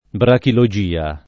brachilogia [ brakilo J& a ] s. f.